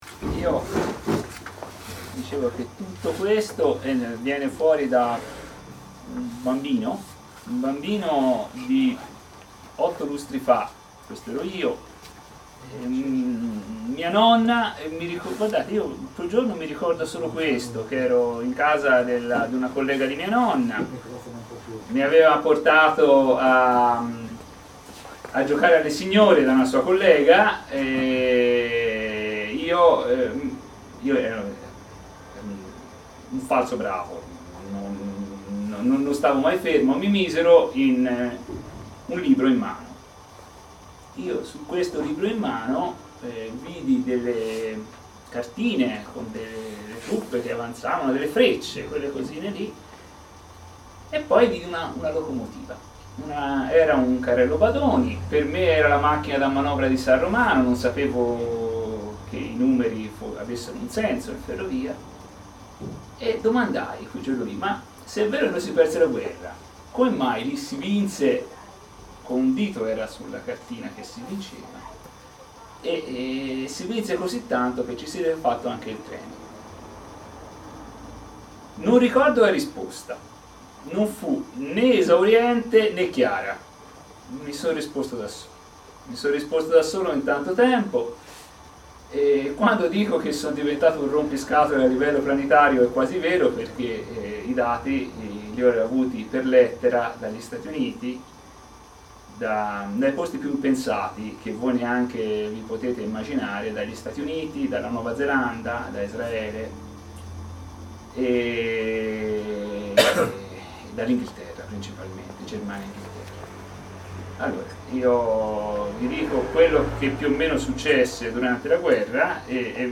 È disponibile la registrazione audio della presentazione del libro del 19 giugno 2015: